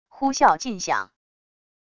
呼啸劲响wav音频